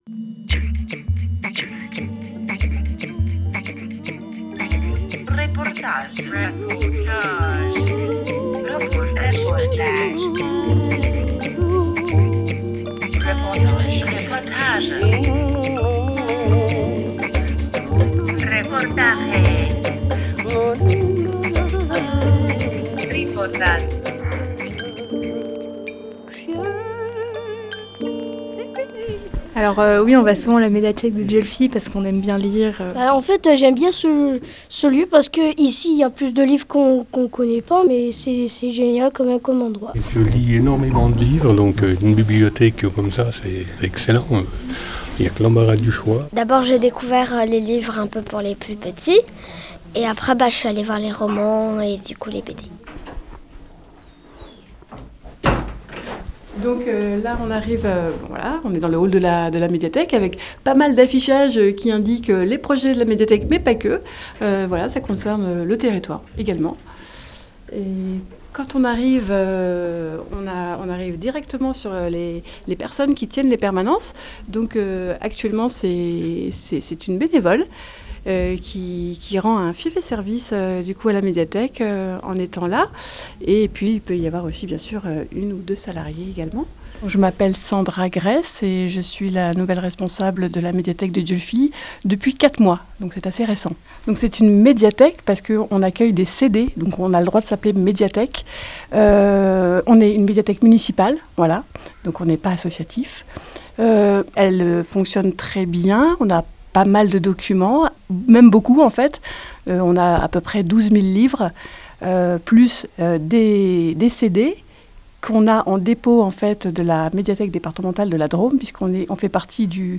Reportage à la médiathèque municipale de Dieulefit
RadioLà est allée promener ses micros à la médiathèque municipale de Dieulefit, un lieu tantôt studieux, tantôt animé, où l’on croise des petits comme des grands lecteurs.
Parole aux reponsables et habitué-es de cette bibliothèque.